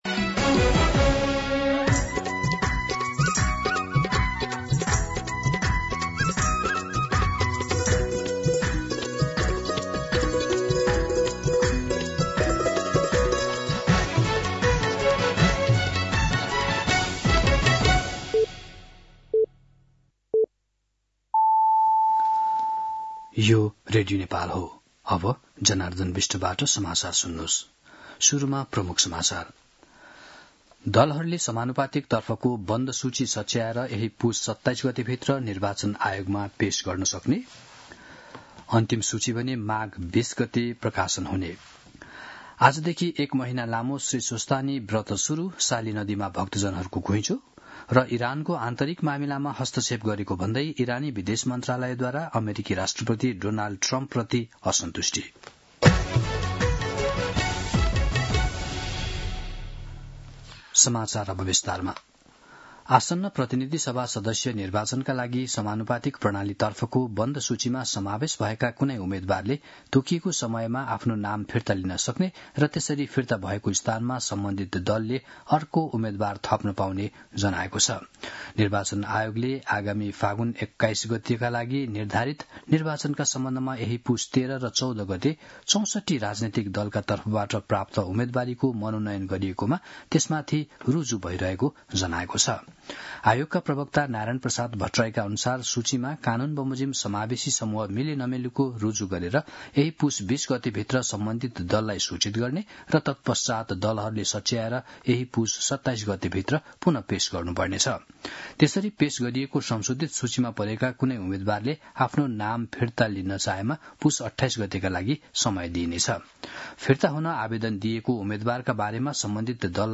3-pm-Nepali-News.mp3